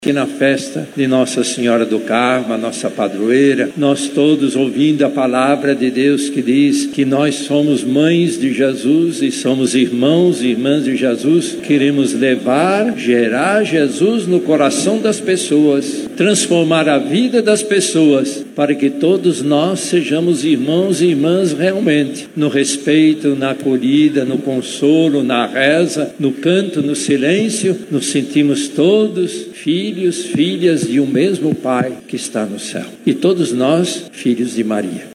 Durante a homilia, o Cardeal Steiner destacou a importância da fé e da proteção de Maria na vida cristã.